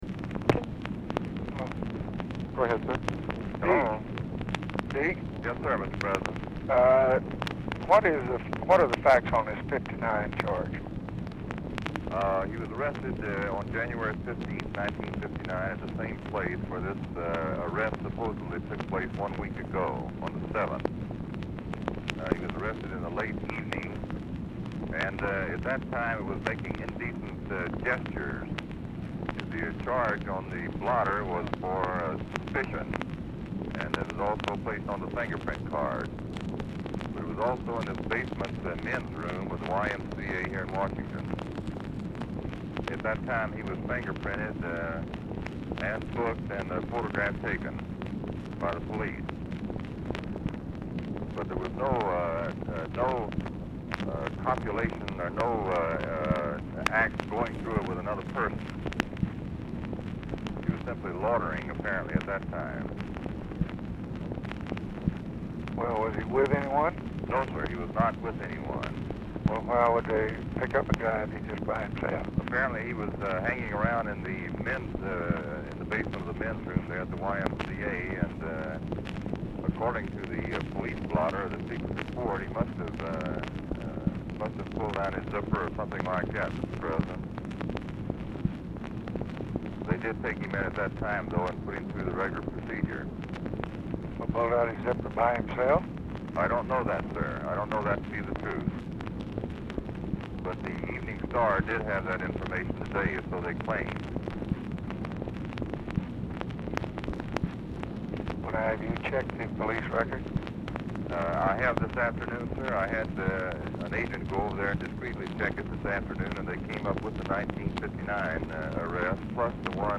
POOR SOUND QUALITY
Format Dictation belt
Specific Item Type Telephone conversation